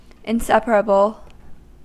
Ääntäminen
Vaihtoehtoiset kirjoitusmuodot (vanhentunut) unseparable Ääntäminen US Tuntematon aksentti: IPA : /in.ˈsɛ.p(ə).rə.bl/ Haettu sana löytyi näillä lähdekielillä: englanti Käännöksiä ei löytynyt valitulle kohdekielelle.